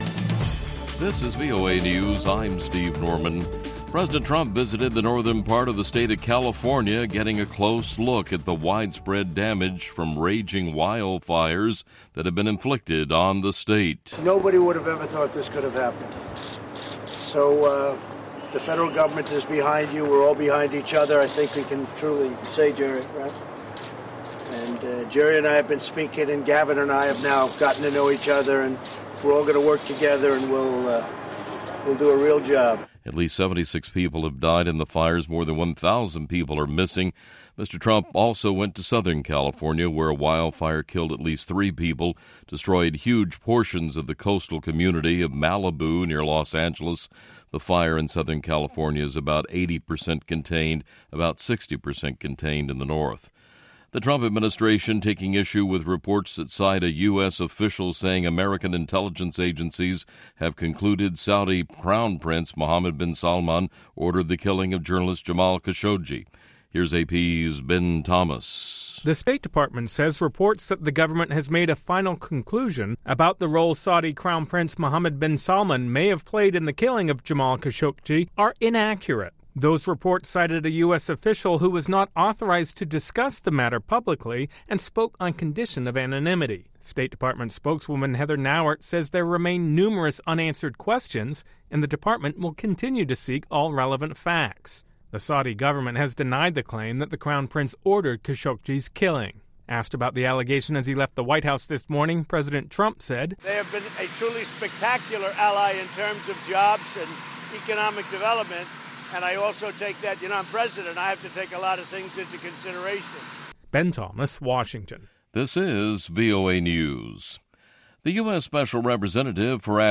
Neosoul